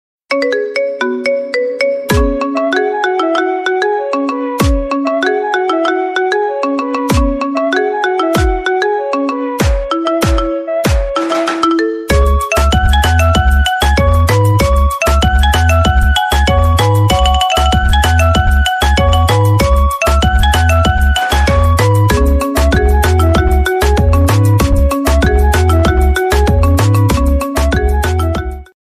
High quality Sri Lankan remix MP3 (0.4).
remix